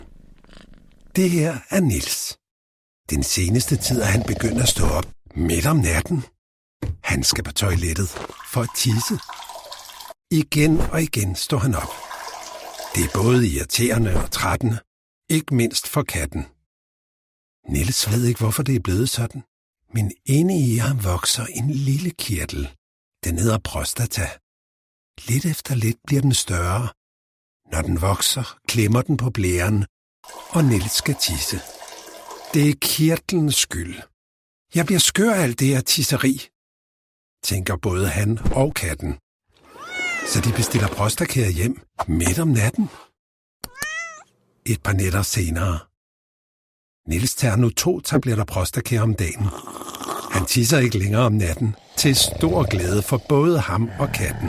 Deep, warm voice.
Character / Cartoon
Danish Commercial Animation